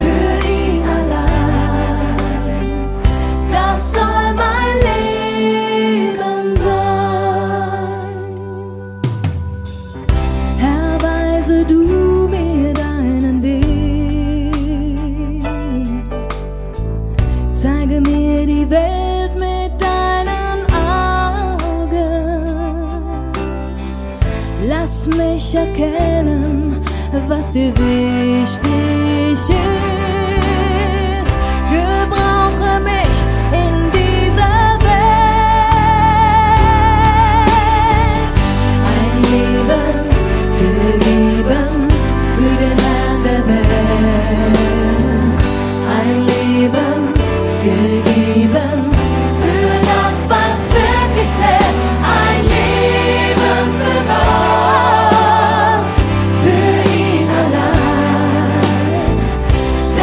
Neuere Gemeindelieder, Worship 0,99 €